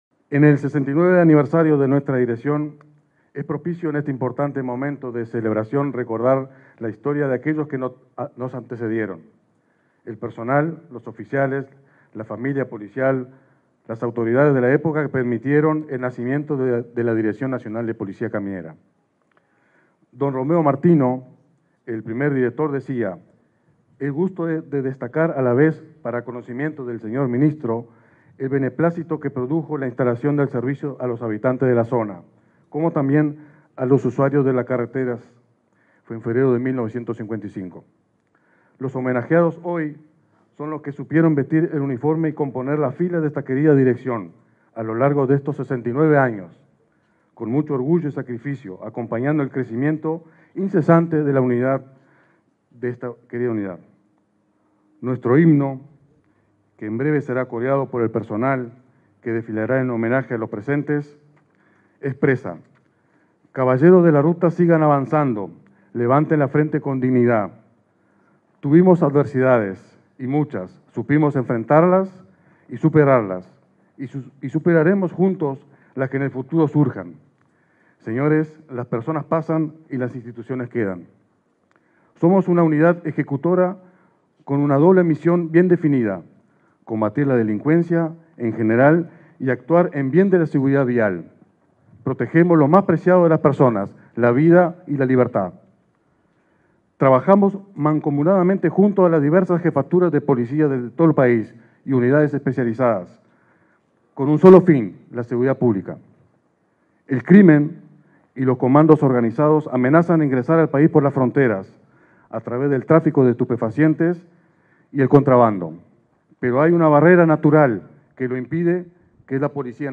Palabras del director nacional de Policía Caminera, Mauricio Tort
Palabras del director nacional de Policía Caminera, Mauricio Tort 15/09/2023 Compartir Facebook X Copiar enlace WhatsApp LinkedIn En el marco de la ceremonia de conmemoración del 69.° aniversario de la Policía Caminera, este 15 de setiembre, se expresó el titular de esa dependencia, Mauricio Tort.